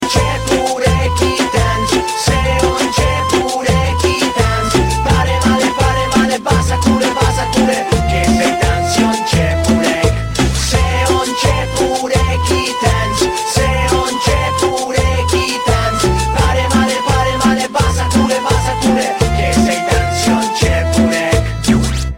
File Type : Funny ringtones